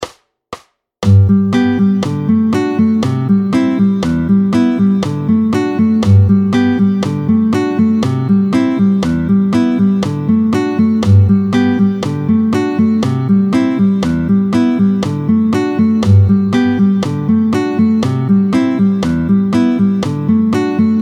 07-02 La mesure à 2/4. Vite, tempo 120